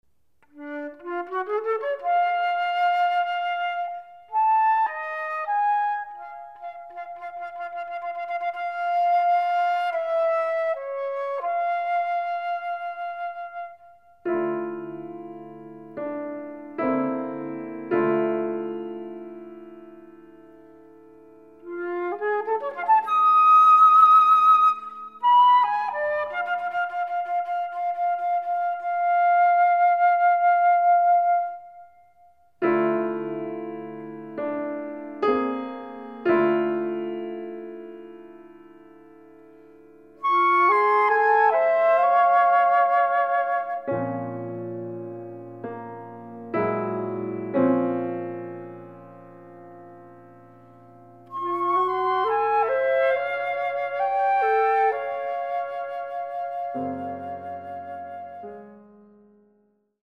anthology of Canadian flute music
pianist